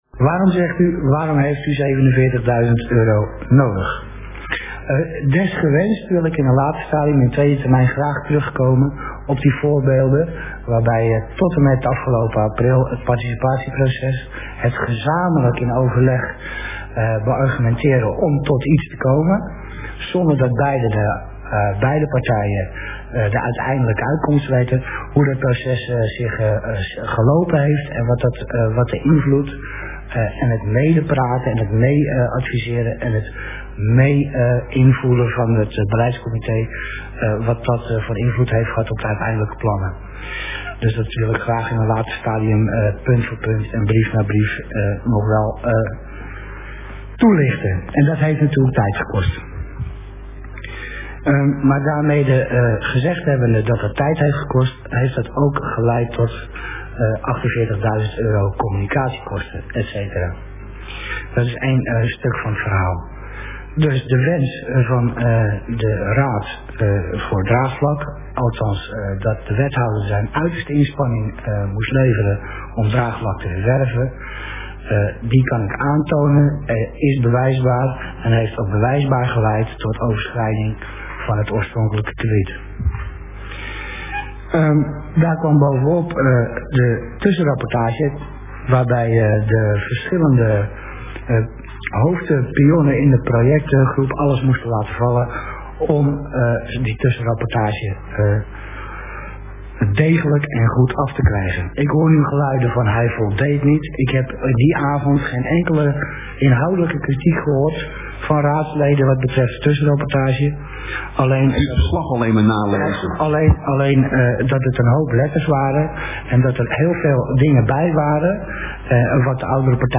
Hieronder vindt u de kern van het betoog van wethouder Demmers (GBZ) op basis waarvan hij op 14 september 2004 een extra krediet van 47000 euro heeft gevraagd en gekregen.
De volgende tekst is woordelijk met weglating van 32 keer 'eeehh' van band overgeschreven.